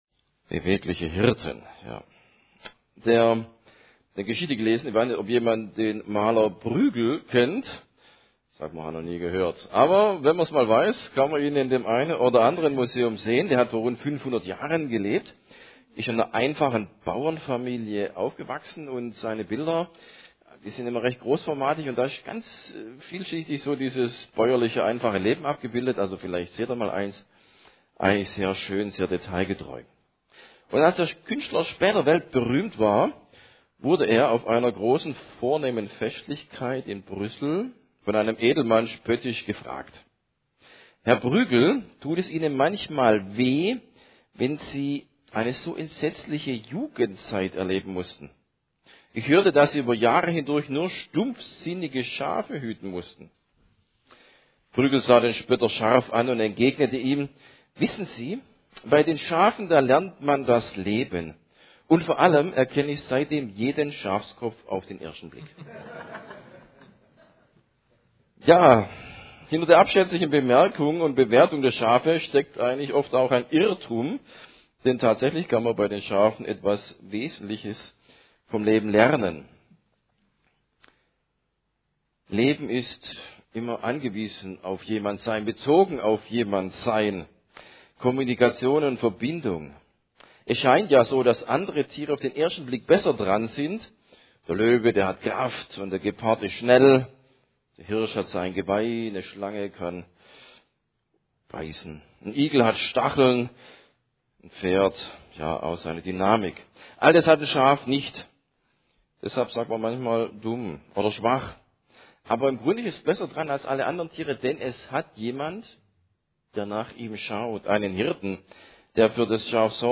SV Ulm: Gottesdienst
Predigt